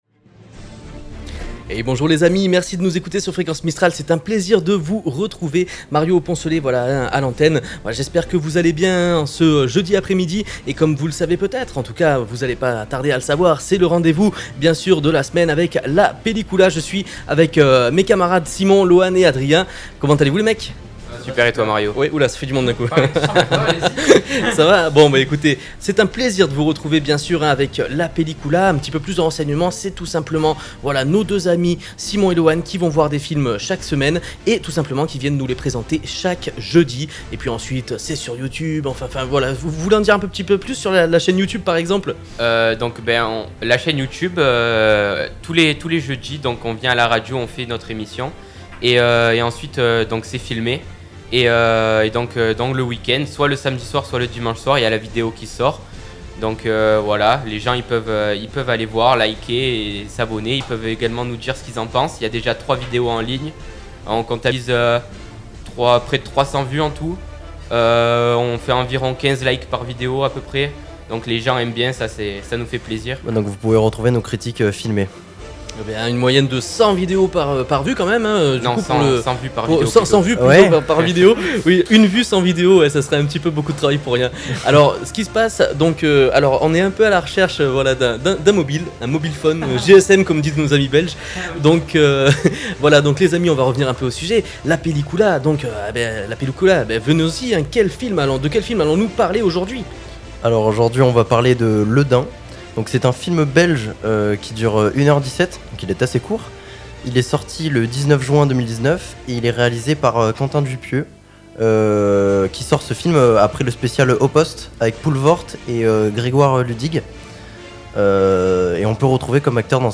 Des films visionnés chaque semaine puis notés par ces derniers pour un projet purement sisteronais auquel se joint avec plaisir Fréquence Mistral, afin de vous tenir informés sur les sorties ciné tous les jeudis en direct à 16h30 !